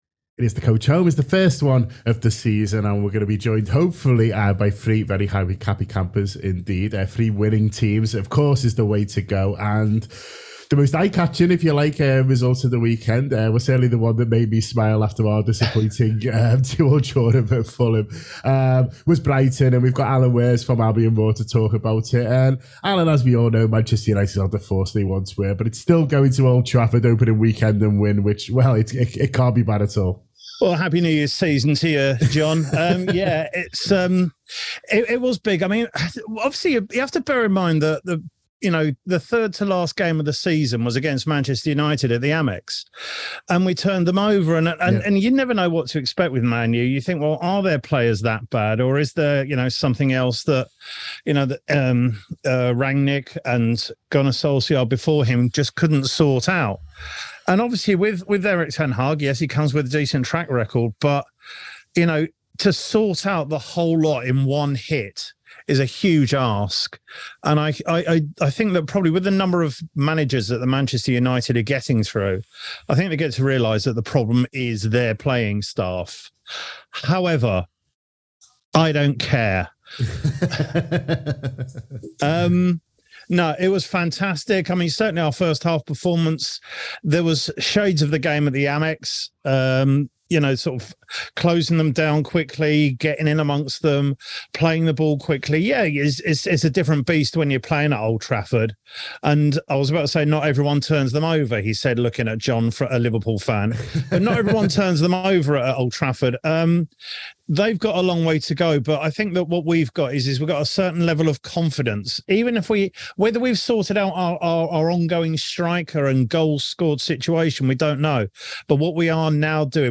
Fan reaction to the weekend’s Premier League results, as Brighton got off to the perfect start with a win at Man United.